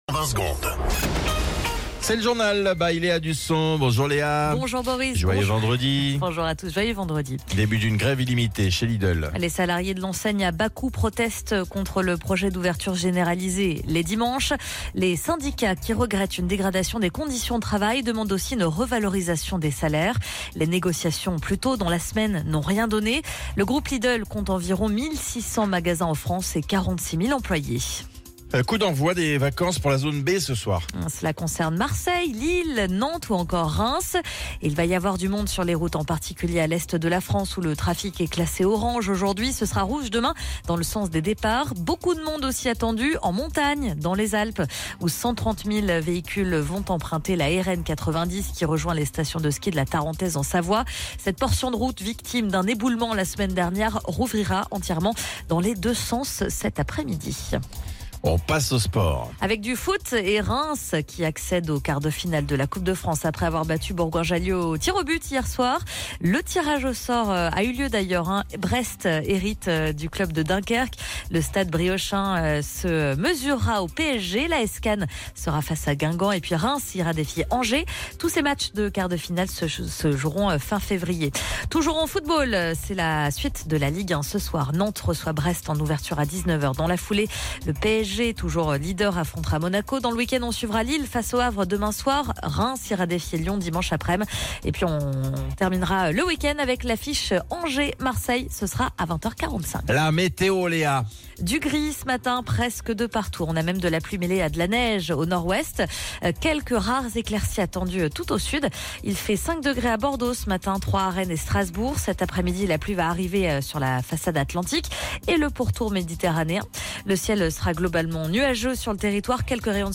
Flash Info National 07 Février 2025 Du 07/02/2025 à 07h10 .